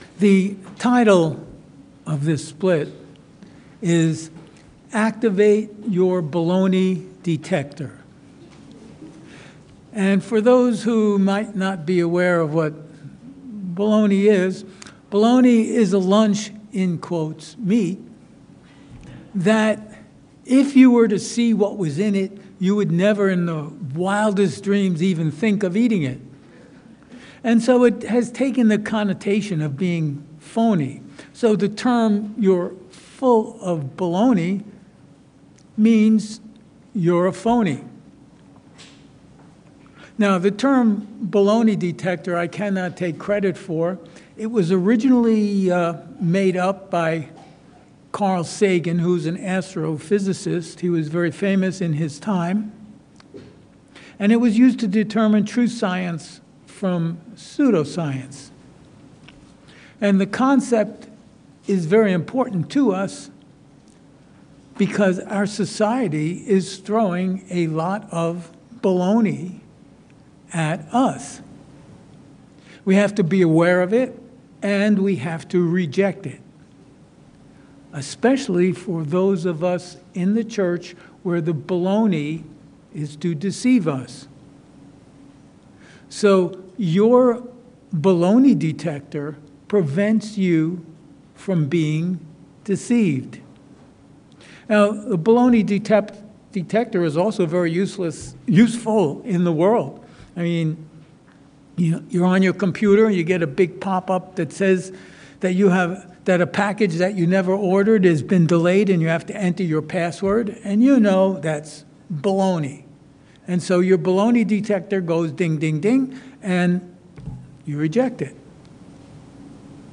Given in Chicago, IL